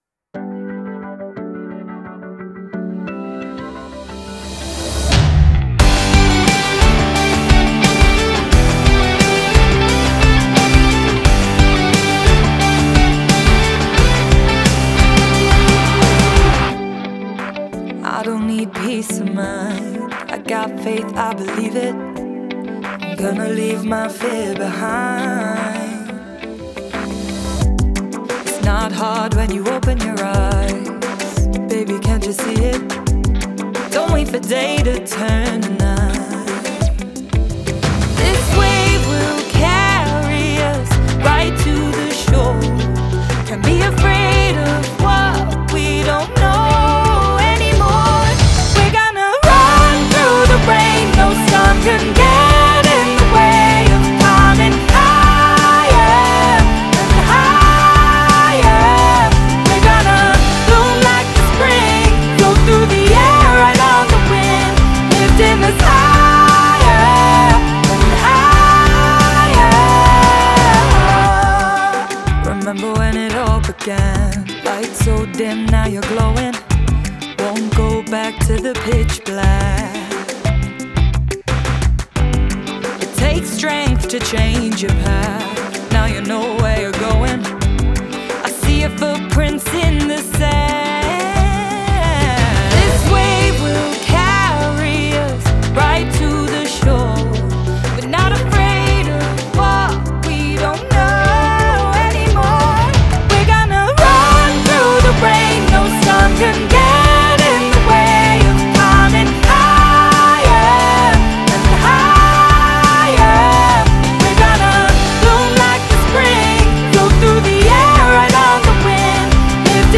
pop rock
Миксы неравнозначны по громкости, но разница подходов слышна.